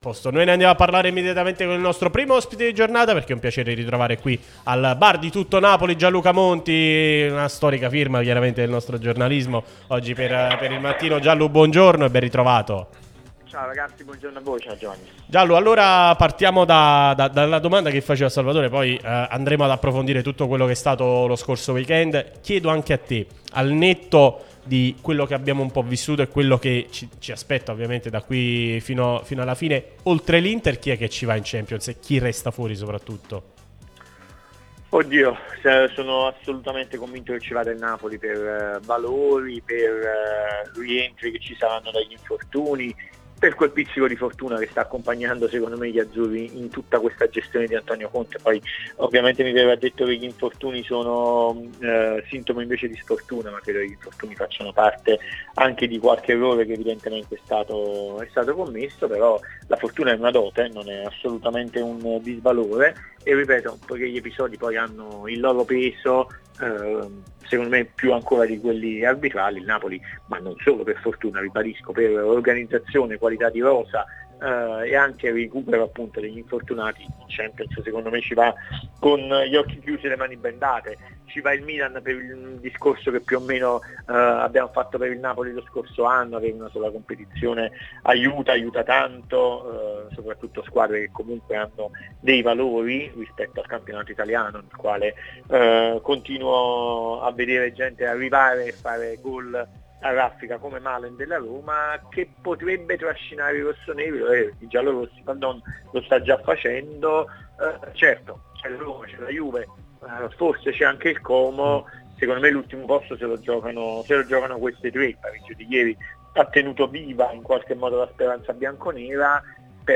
Radio Tutto Napoli